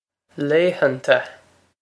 laethanta lay-han-ta
Pronunciation for how to say
This is an approximate phonetic pronunciation of the phrase.